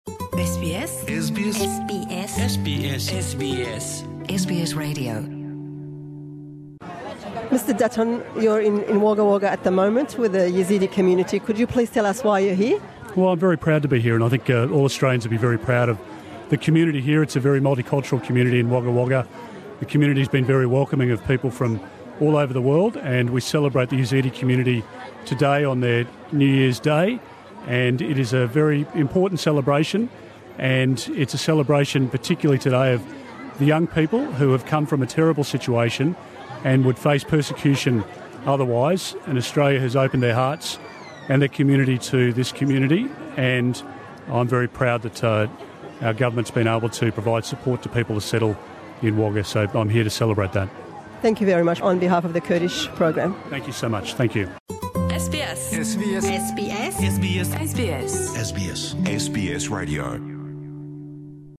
Me kurte hevpeyvn bi Wezîrê Koçberiyê û Parastina Sînor berz Peter Dutton re sebaret bi serdana civaka Êzdî li Wagga Wagga pêk an. Berêz Dutton jibo pîrozbahiya Ser Sala Êzîdiyan serdana Wagga Wagga Kir û got ew gelekî kêfxweshe ku hukmata Australya Êzîdiyan li Austraya bi cîh dike.